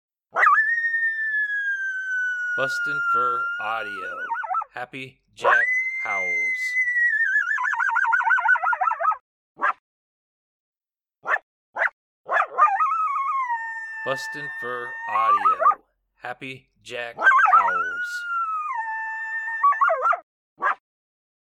Male Juvenile Coyote howling with authority! Excellent howl to answer responding vocal coyotes.